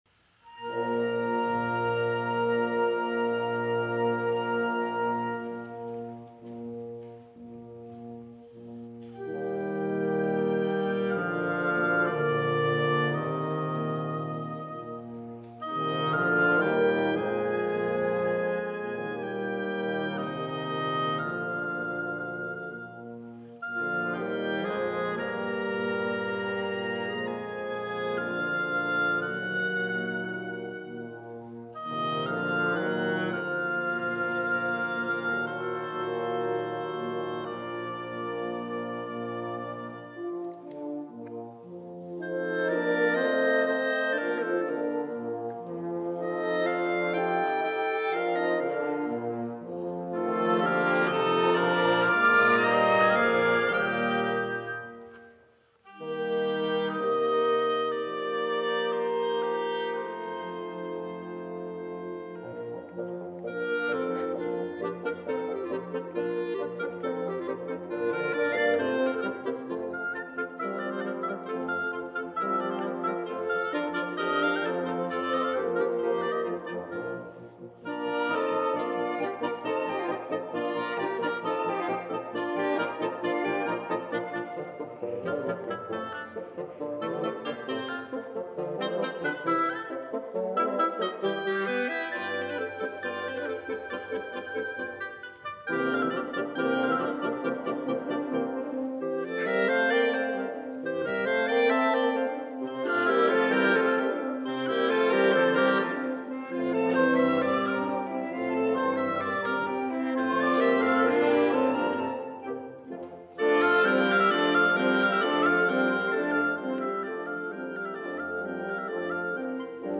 L'Ensemble di fiati della Filarmonica Arturo Toscanini è composto da:
flauto
oboe
clarinetto
fagotto
corno
Musica Classica / Cameristica